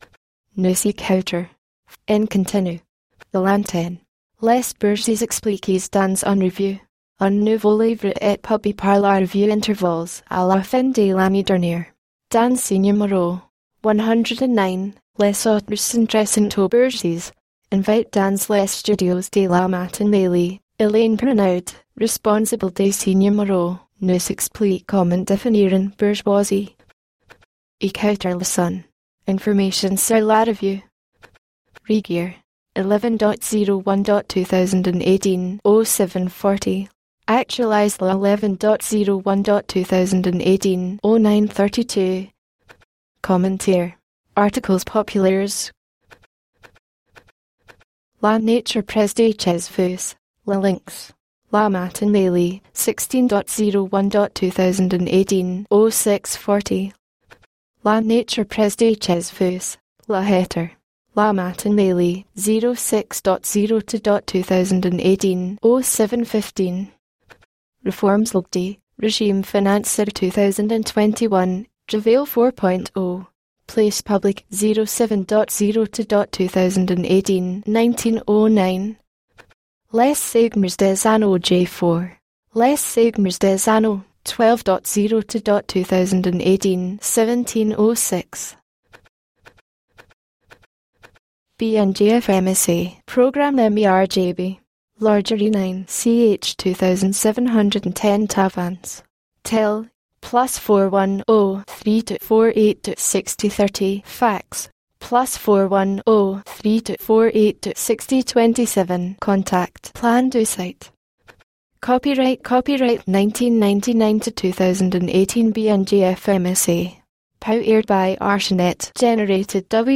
Vers l’interview original